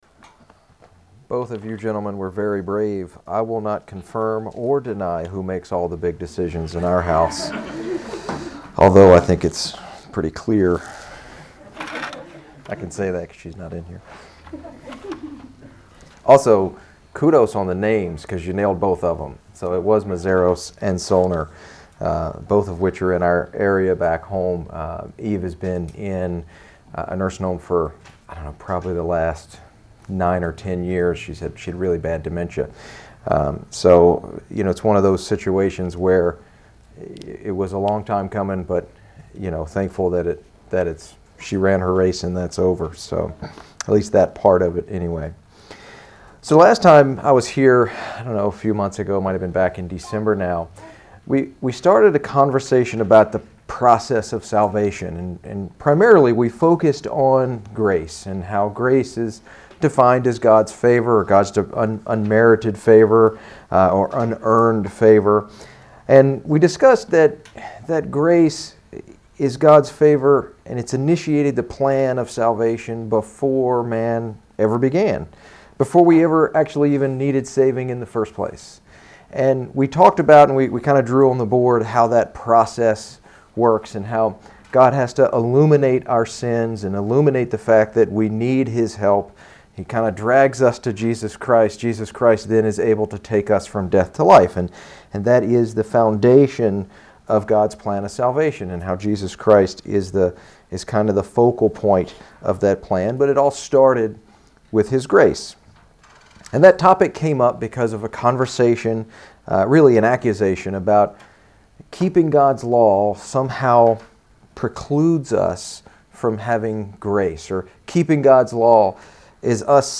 This series of sermons are designed to look at the basic building blocks of God’s plan of salvation. We will explore Grace, Law, and Love as it pertains to Salvation.